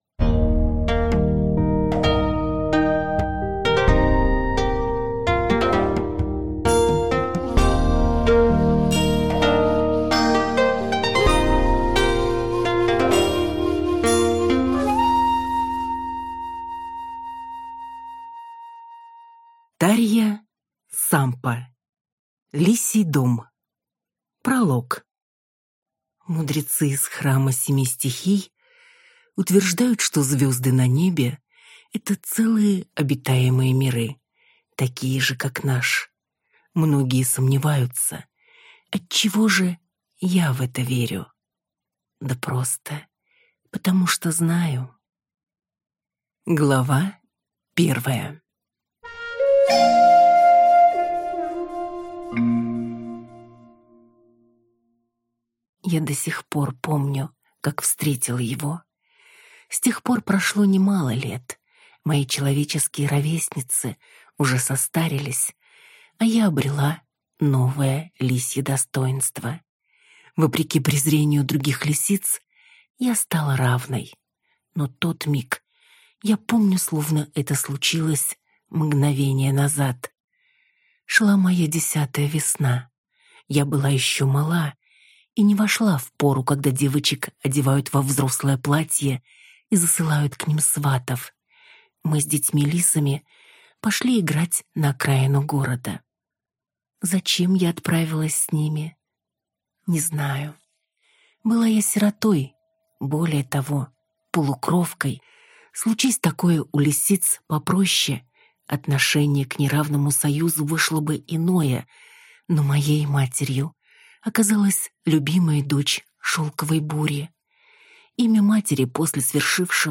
Аудиокнига Лисий дом | Библиотека аудиокниг